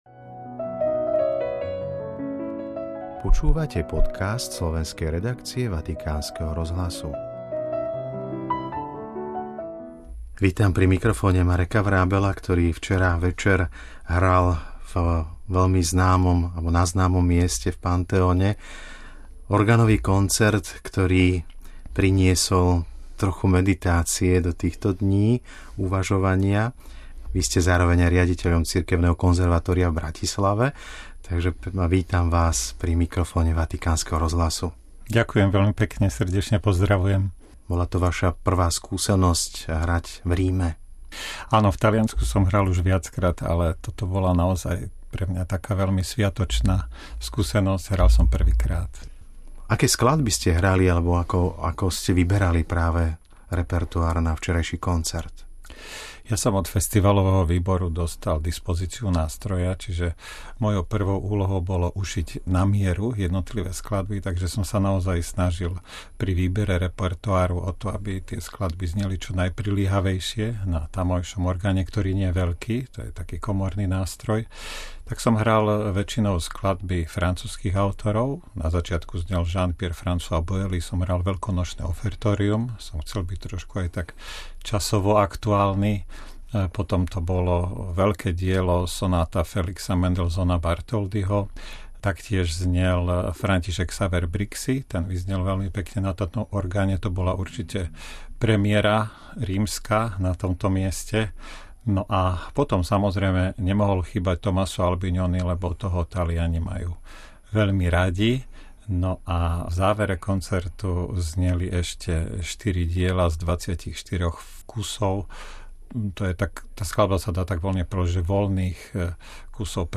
NAŽIVO
Historický chrám vo Večnom meste sa zaplnil majestátnym zvukom organu, ktorý pod rukami renomovaného umelca rozozvučal nielen kamenné steny, ale i srdcia publika.